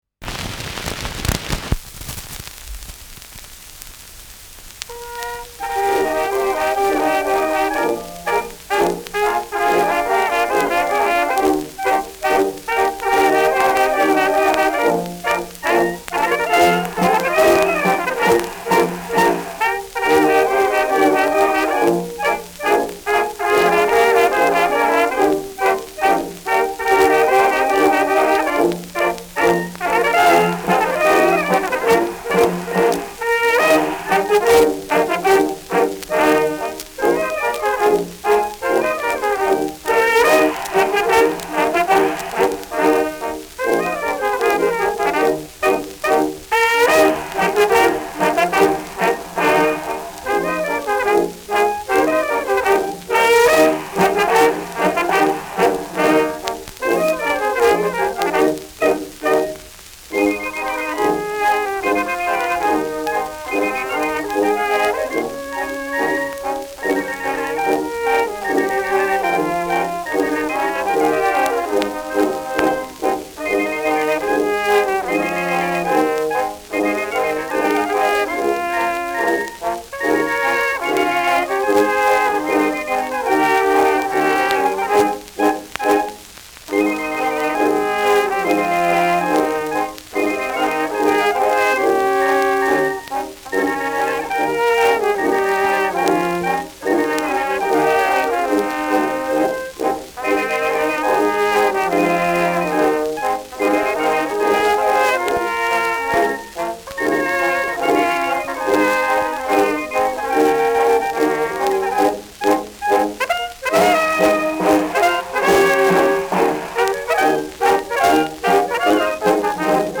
Schellackplatte
Abgespielt : Gelegentlich leichtes Knacken : Leichtes Nadelgeräusch